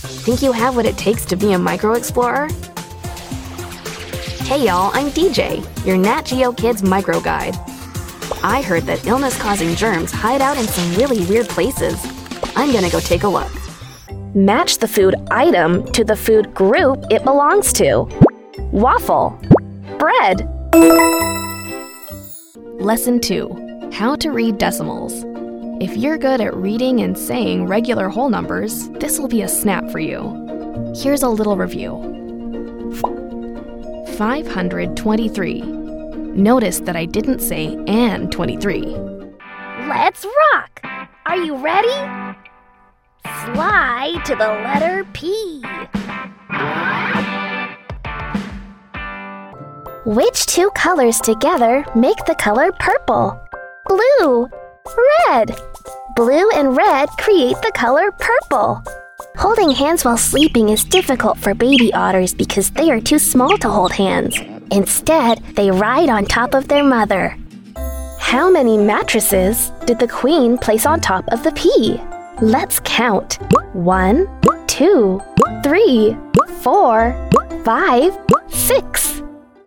Englisch (Amerikanisch)
Kommerziell, Natürlich, Verspielt, Vielseitig, Freundlich
E-learning